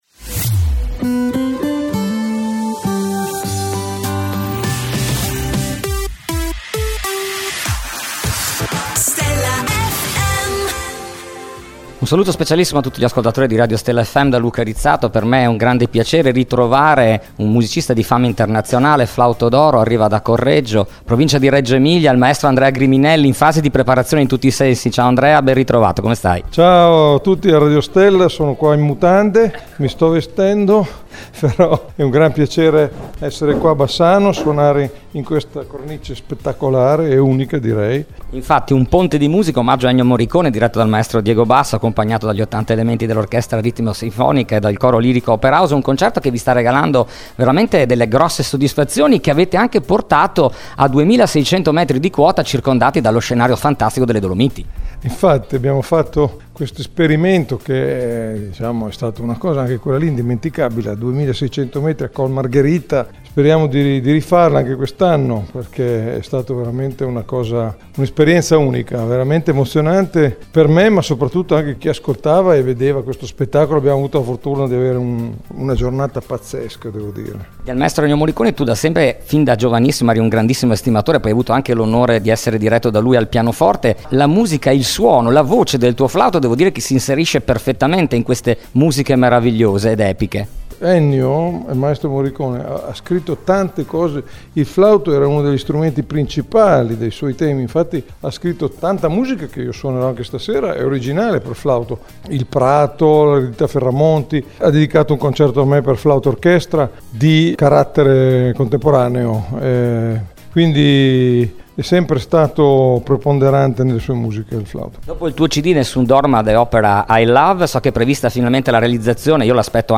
Intervista esclusiva dell’inviato per Stella FM a Andrea Griminelli.